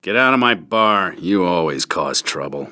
―Timmy to Kyle Katarn — (audio)
NarShad_Bartender_Always_Cause_Trouble.ogg